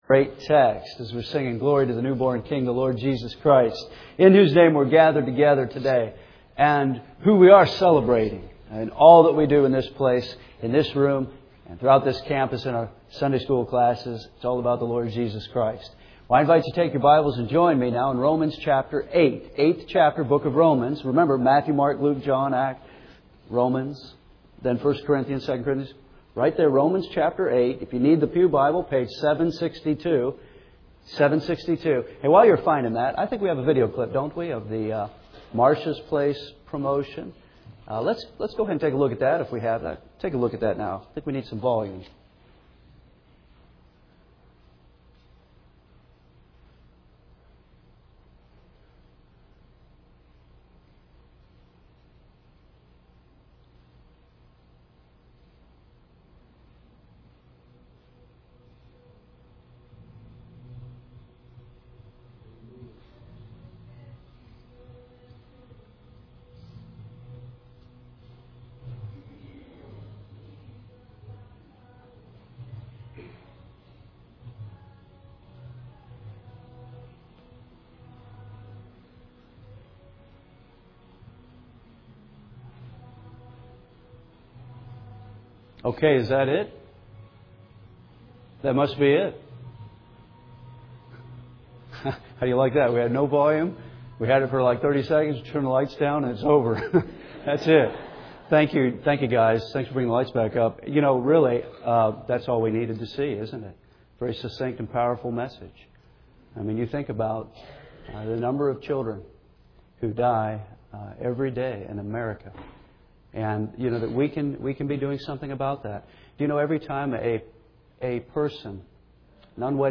We’re continuing our verse-by-verse expository preaching of the Book of Romans.